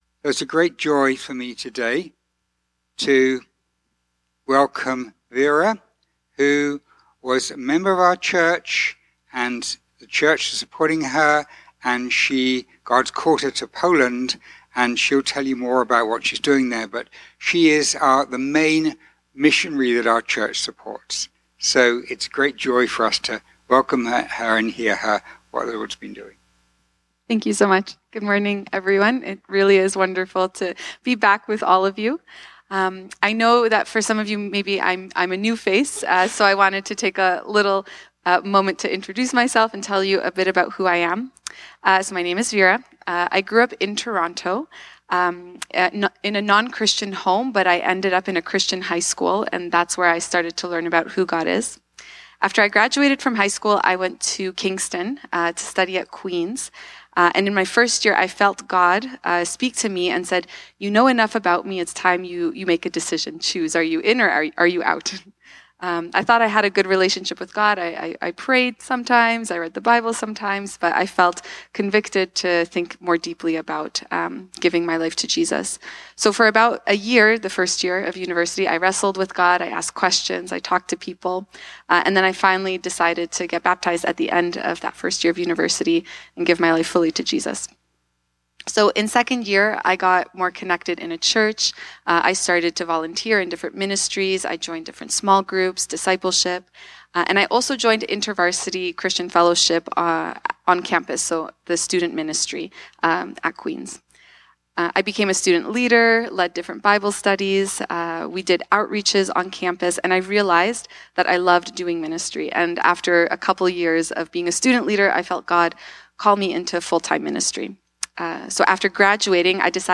Most recent Sermon Videos